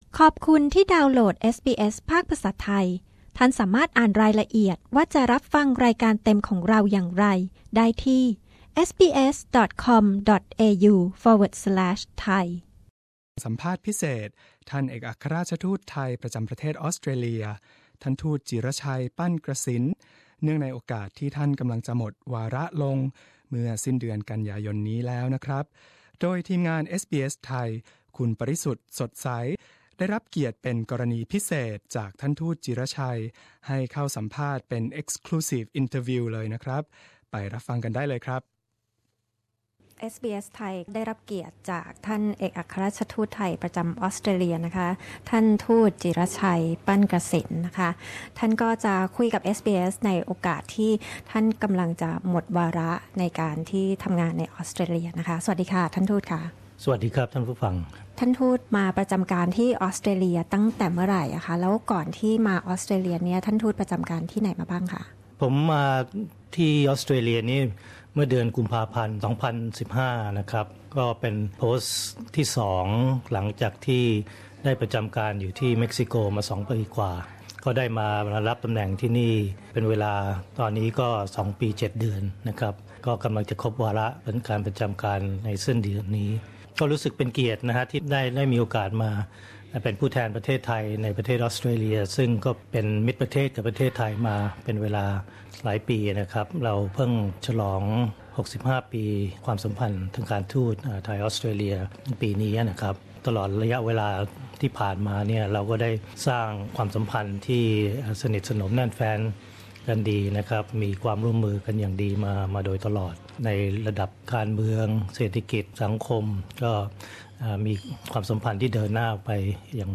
สัมภาษณ์พิเศษ ท่านเอกอัครราชทูตไทยประจำออสเตรเลีย เนื่องในโอกาสหมดวาระ
เอกอัครราชทูตไทย ประจำประเทศออสเตรเลีย ท่านทูตจิระชัย ปั้นกระษิณ ให้เกียรติแก่รายการ SBS Thai เป็นกรณีพิเศษ ให้สัมภาษณ์เนื่องในโอกาสหมดวาระ